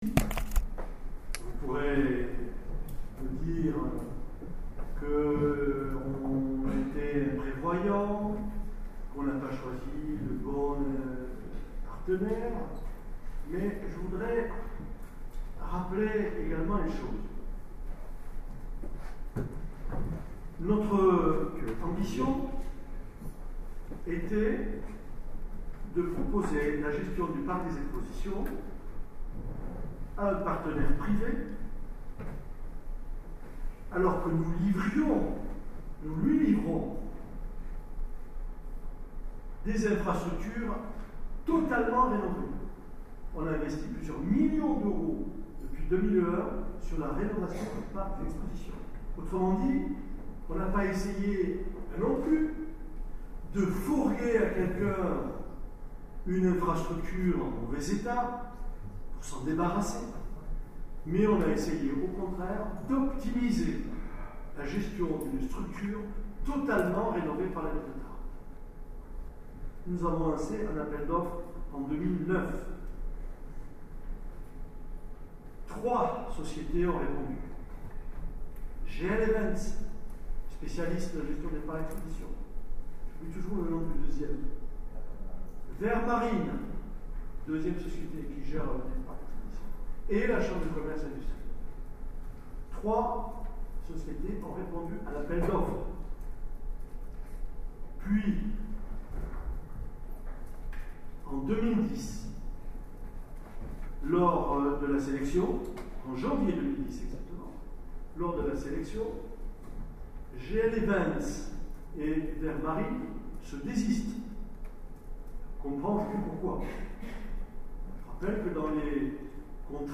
Ci-dessous, en audio, l’intervention de Gérard Trémège et le débat où sont intervenus : Dominique Montamat (Front de Gauche, Marie-Pierre Vieu (Front de gauche) et Claude Gaits (PRG)